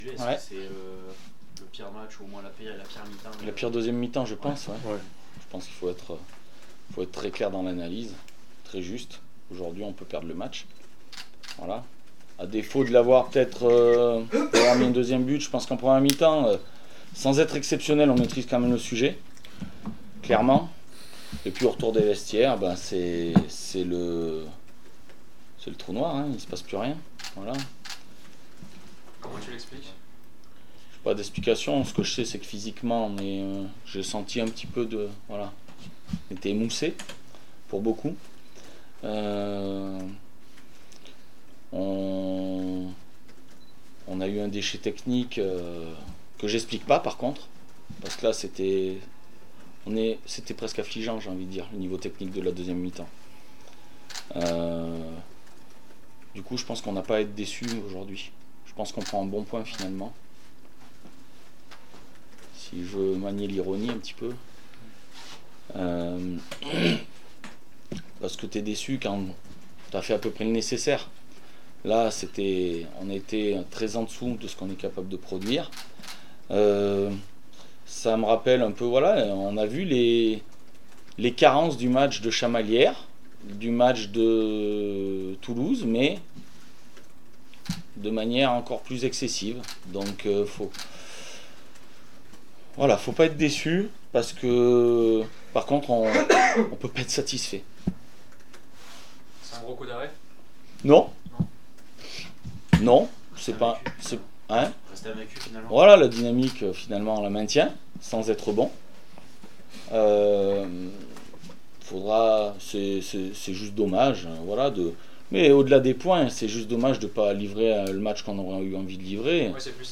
6 avril 2024   1 - Sport, 1 - Vos interviews